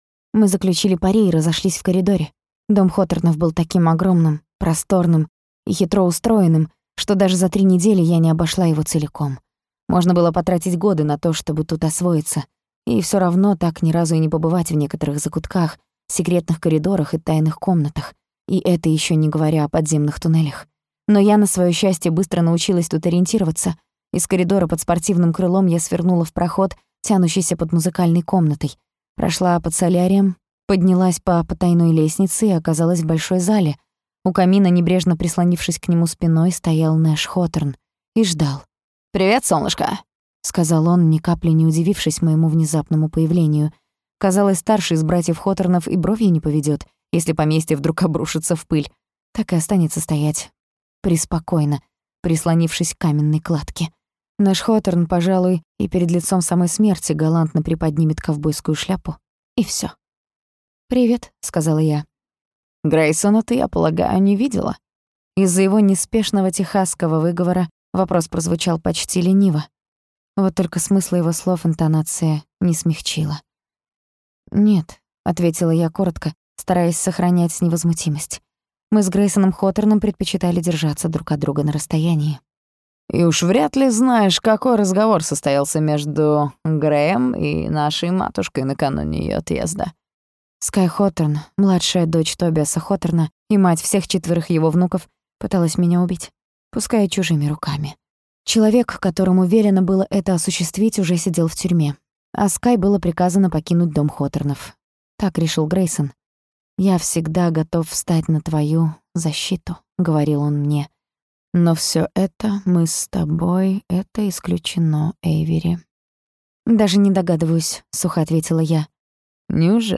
Аудиокнига Наследие Хоторнов | Библиотека аудиокниг
Прослушать и бесплатно скачать фрагмент аудиокниги